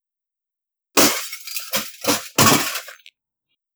glass_breaking50.wav